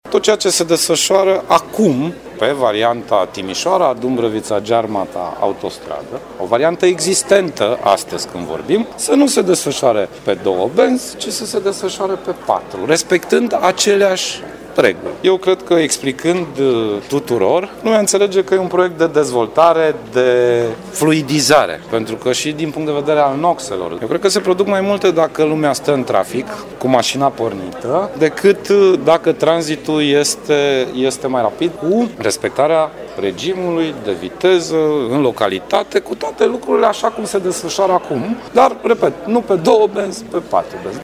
Președintele CJ Timiș, Sorin Grindeanu, spune că în ciuda opoziției unor localnici, proiectul va fi benefic pentru comună.